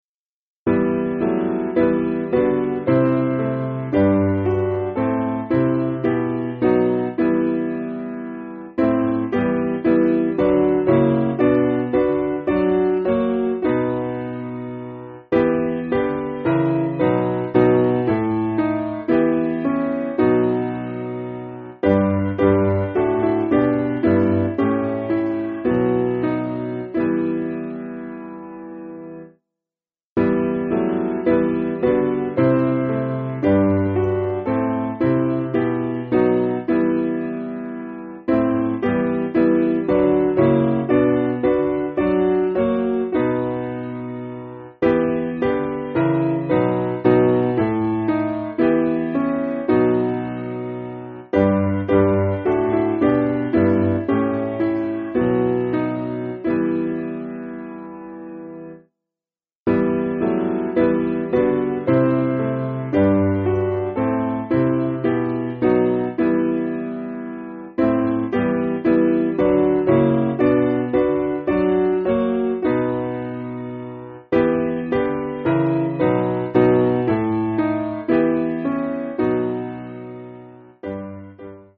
Simple Piano
(CM)   5/Em